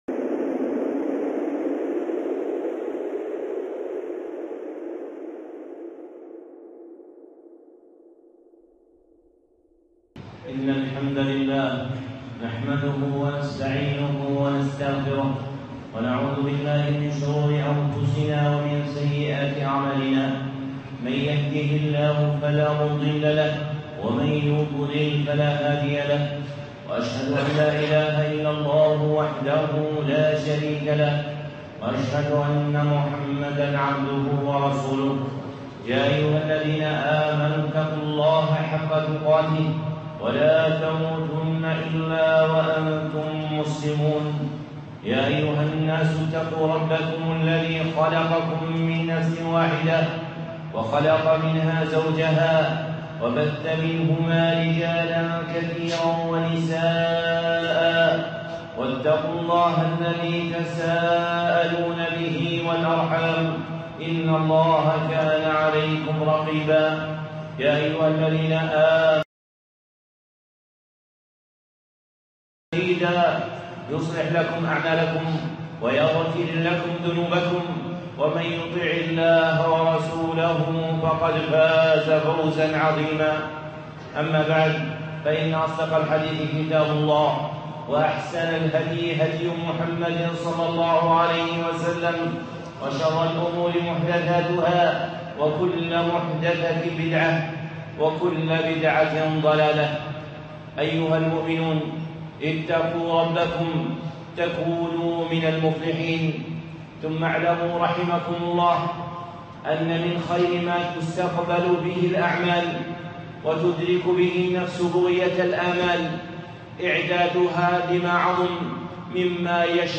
خطبة (عدة الإنسان في استقبال رمضان